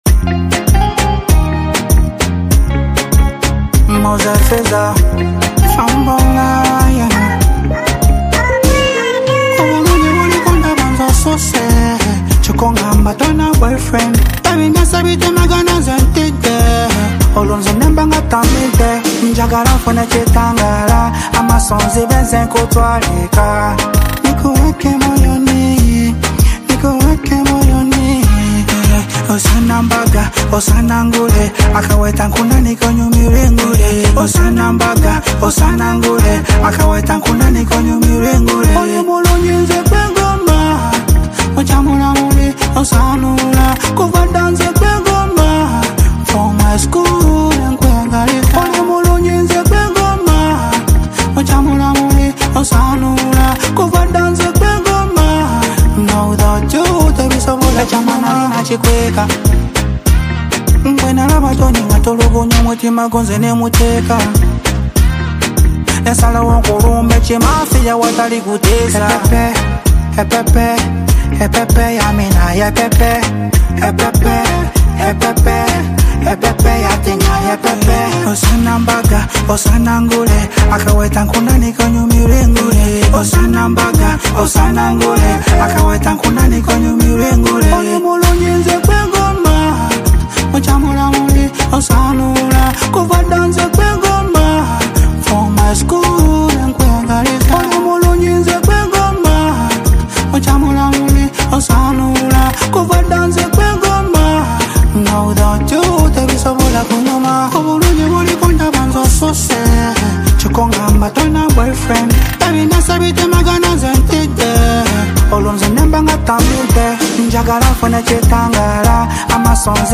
a trending Ugandan dancehall song